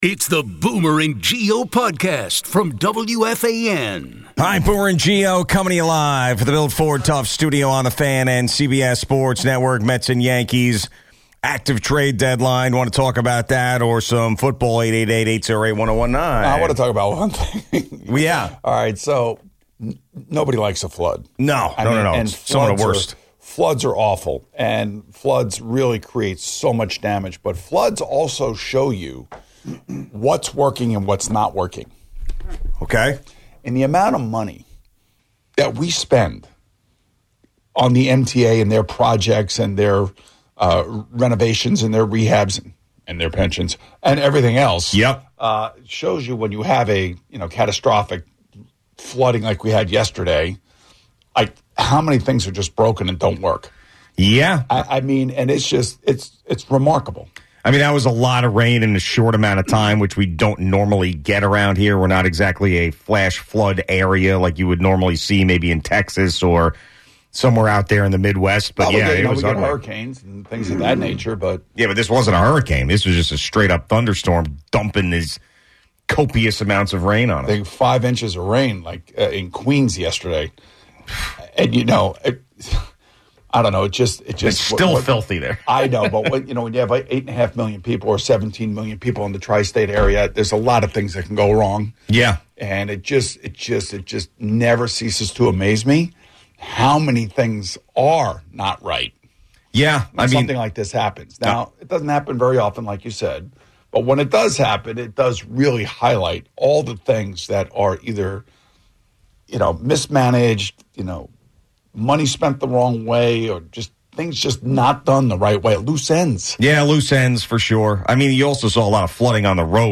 A caller, close to Robin Ventura, believes Ventura won his fight with Nolan Ryan. We recall the 1999-2000 Mets.